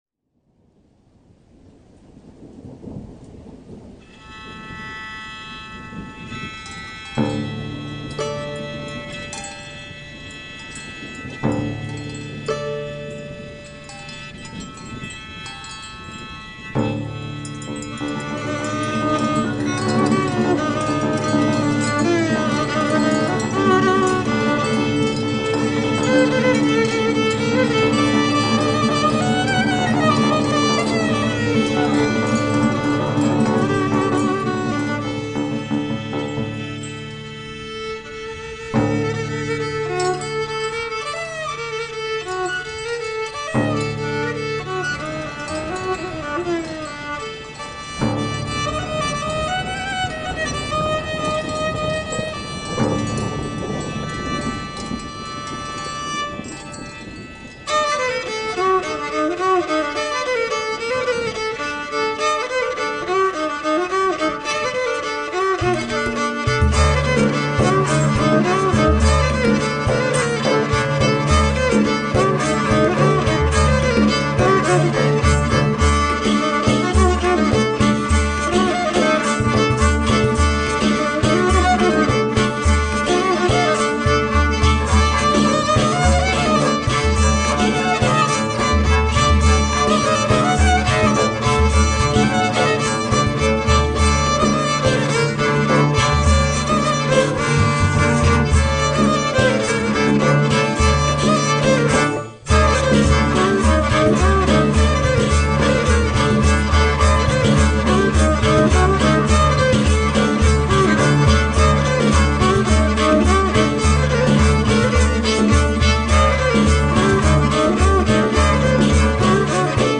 Medieval european, turkish and arabic music.
Tagged as: World, Renaissance, Arabic influenced